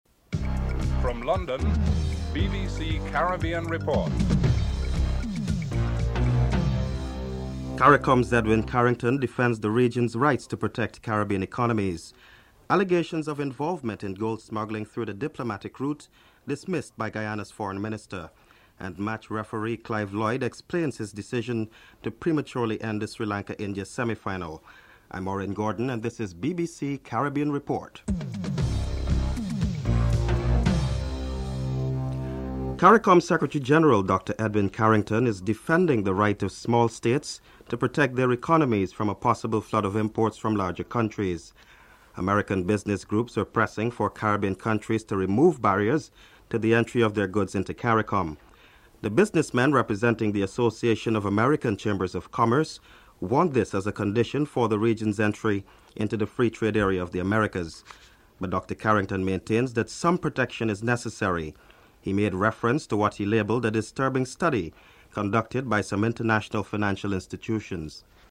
1. Headlines (00:00-00:30)
Caricom General Secretary Edwin Carrington is interviewed (00:32-02:19)
Former West Indies captain Clive Lloyd is interviewed (12:57-15:11)